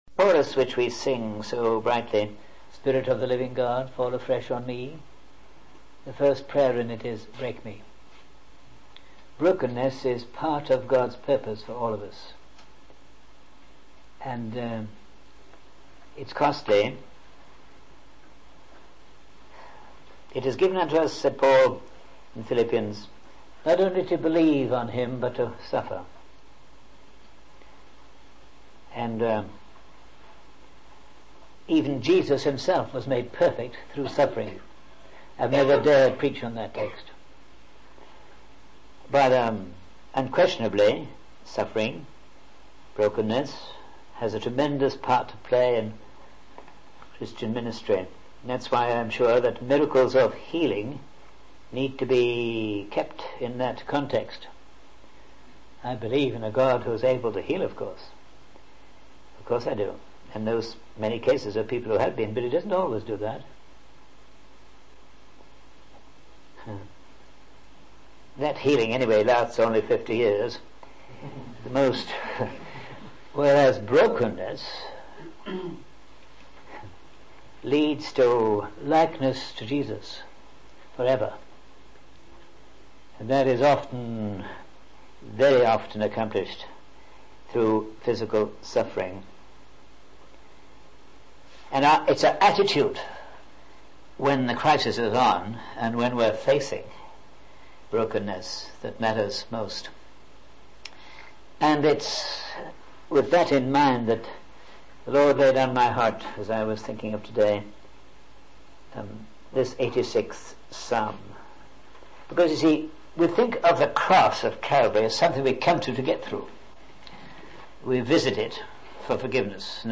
In this sermon, the speaker encourages the audience to reflect on their convictions about God. He suggests that they circle three titles for God mentioned by David in the psalm.